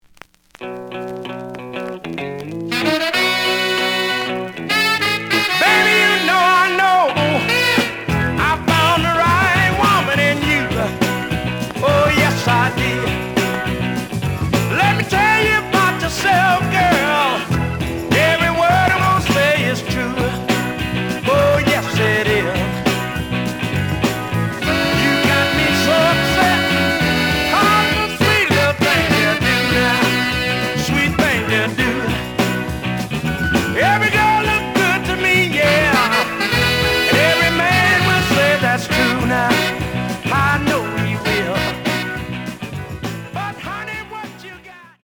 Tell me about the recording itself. The audio sample is recorded from the actual item. Slight damage on both side labels. Plays good.)